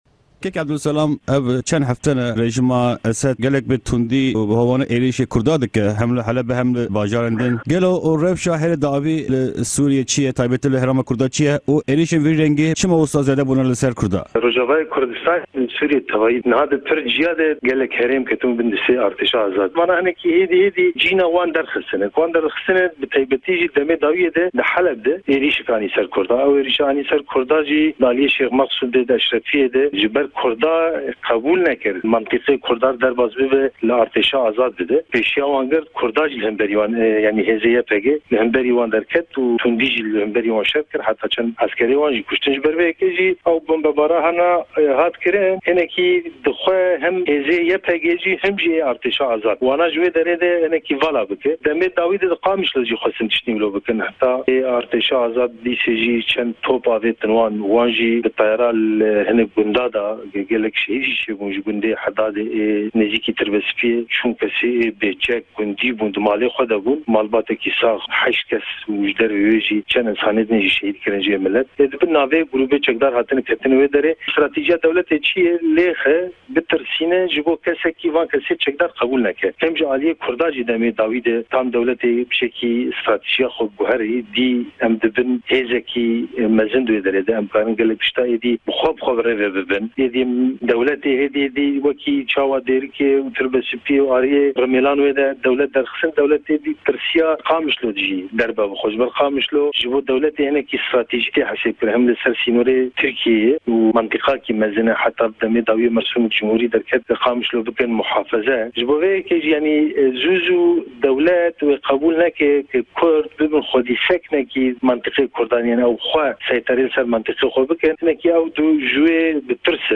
Di hevpeyvîneke Dengê Amerîka de